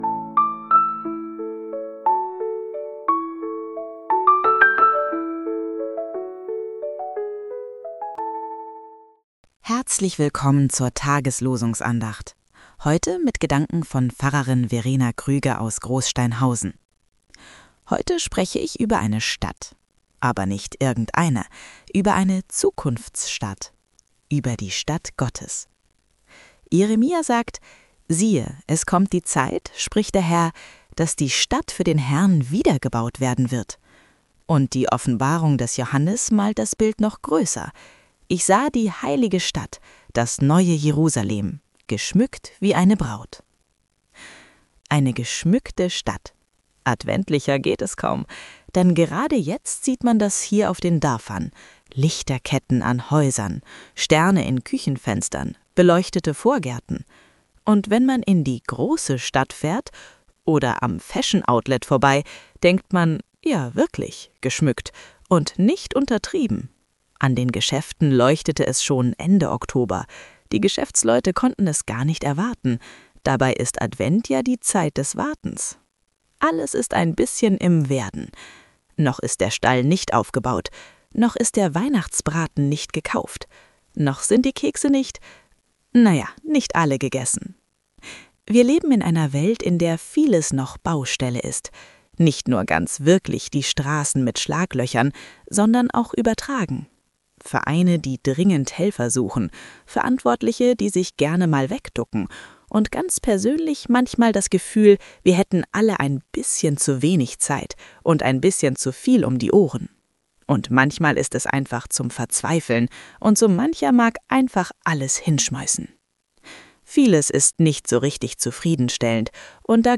Losungsandacht für Samstag, 13.12.2025 – Prot.